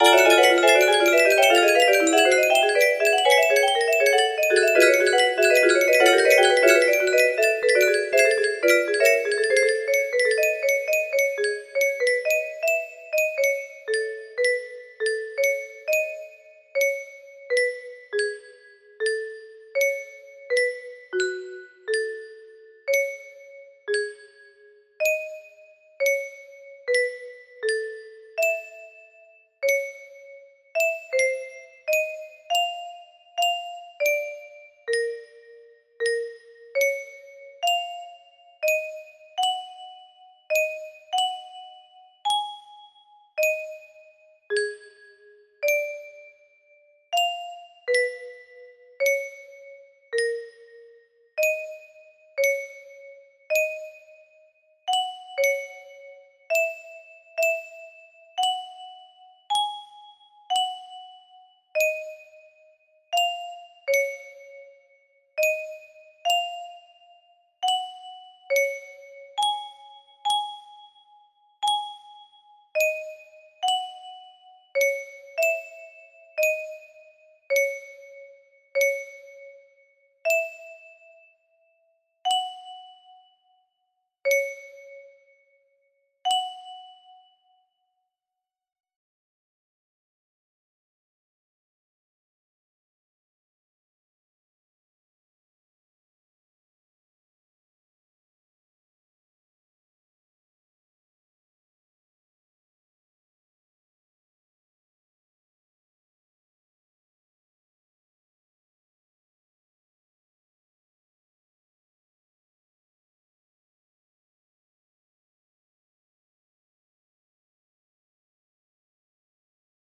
222 music box melody